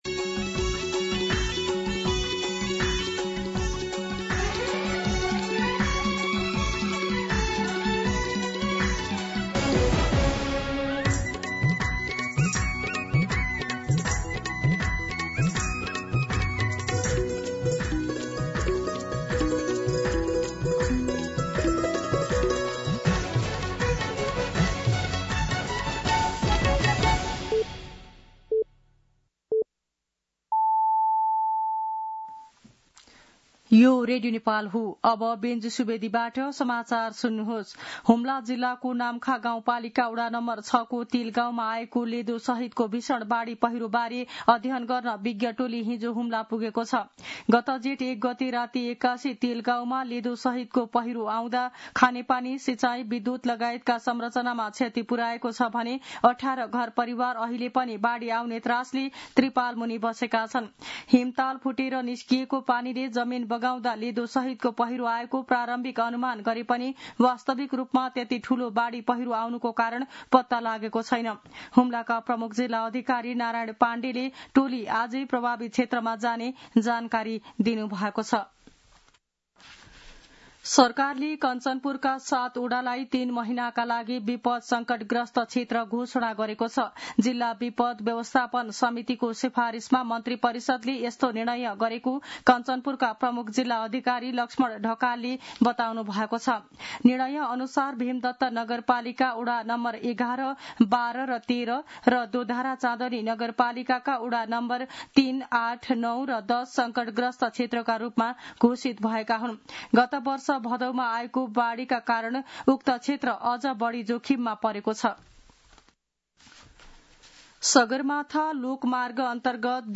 दिउँसो १ बजेको नेपाली समाचार : ८ जेठ , २०८२